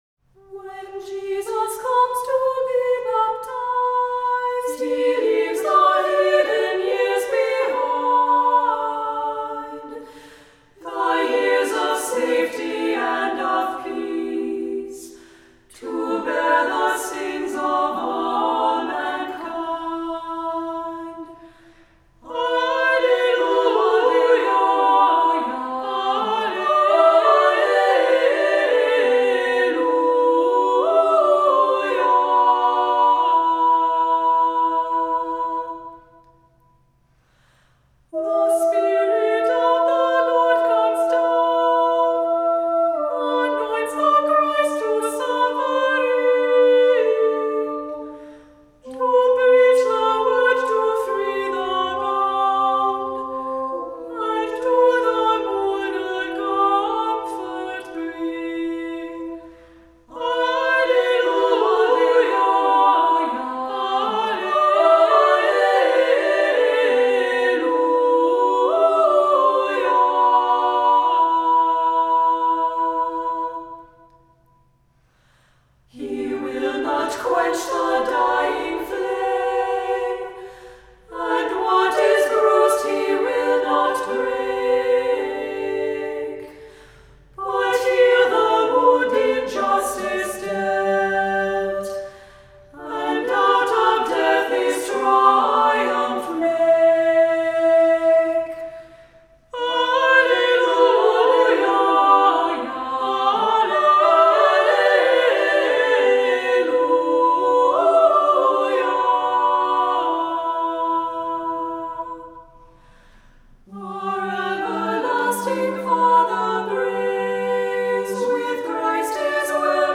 Voicing: Three-part choir a cappella